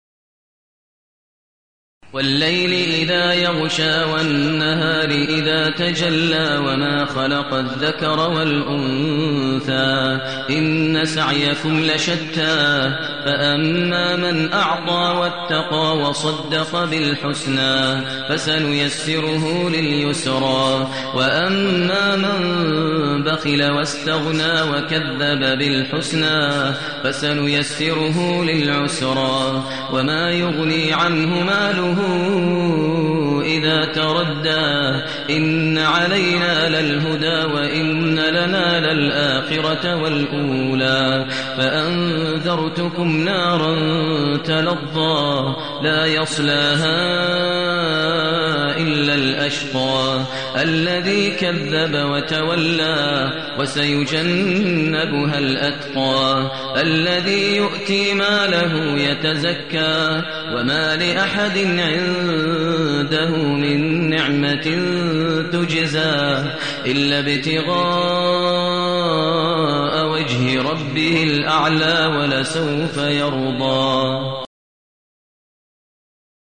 المكان: المسجد النبوي الشيخ: فضيلة الشيخ ماهر المعيقلي فضيلة الشيخ ماهر المعيقلي الليل